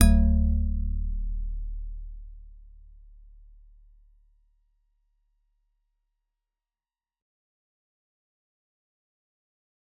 G_Musicbox-D1-f.wav